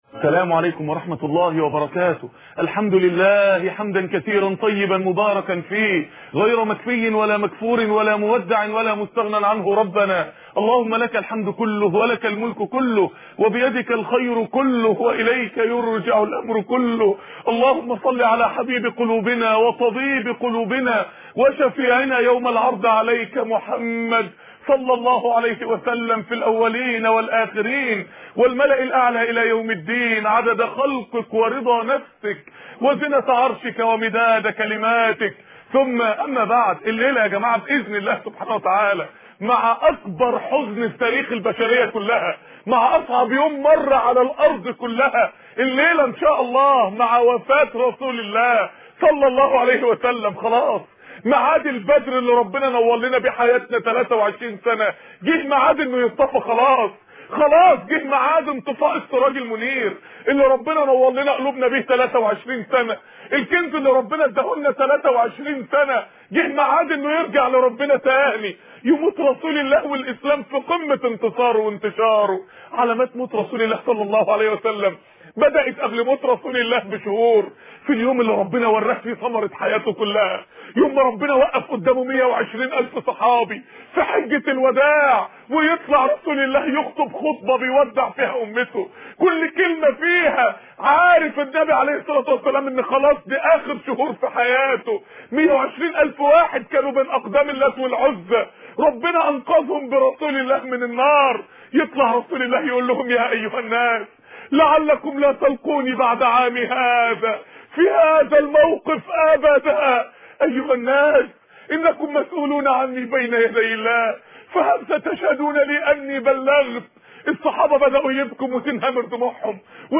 السنة المطهرة خطب ومحاضرات لماذا محمد ؟